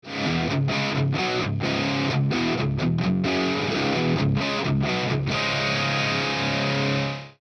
UK 45 – Marshall JTM45 – Normal Channel
No post processing was added.
JN-Brit-Crunch.mp3